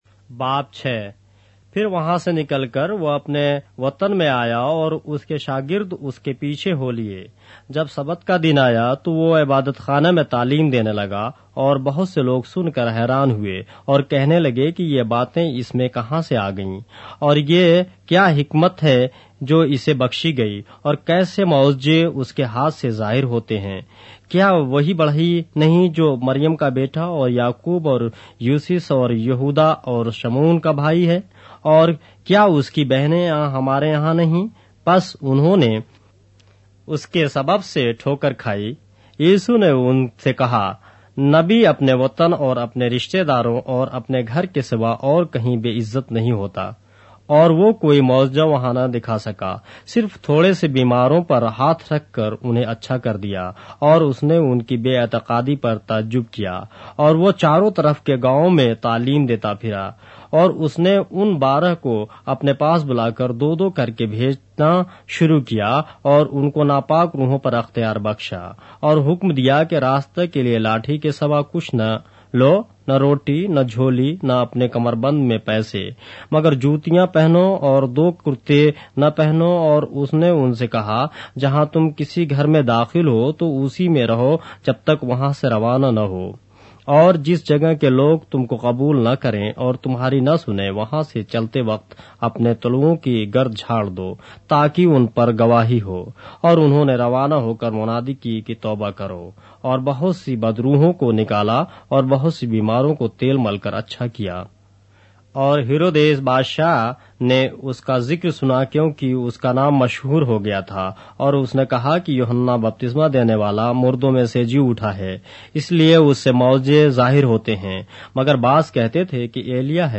اردو بائبل کے باب - آڈیو روایت کے ساتھ - Mark, chapter 6 of the Holy Bible in Urdu